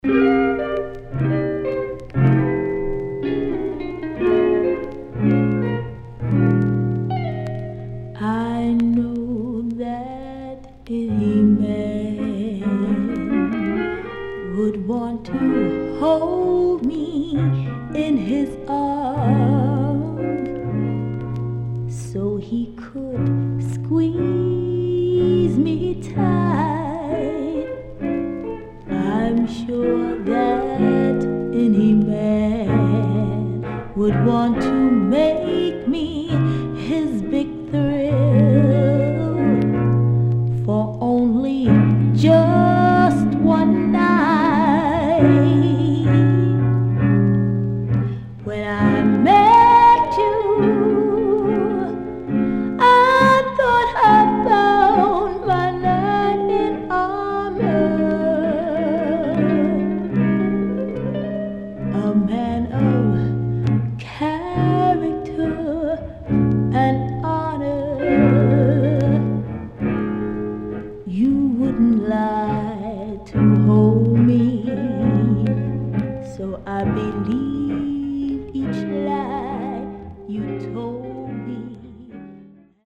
HOME > SOUL / OTHERS
SIDE A:少しチリノイズ入ります。